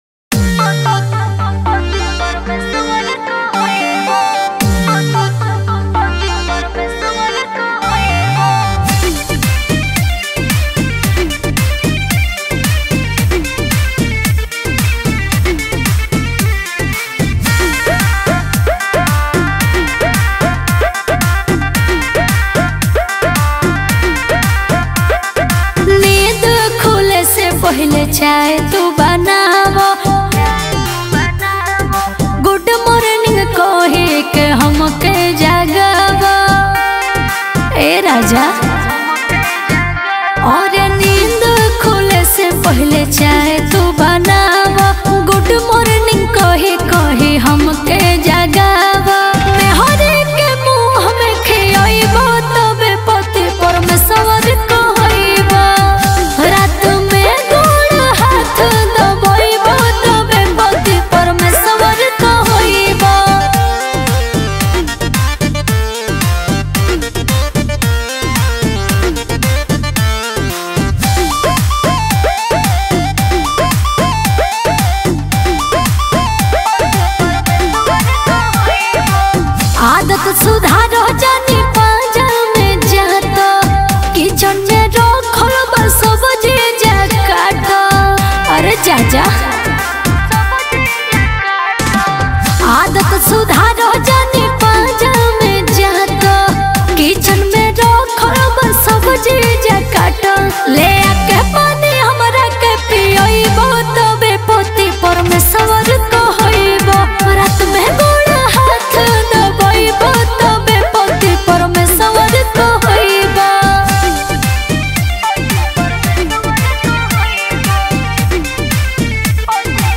Bhojpuri